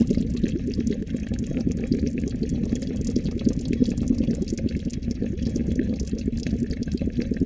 water_bubbling_02_loop.wav